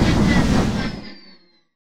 stormimpact02.wav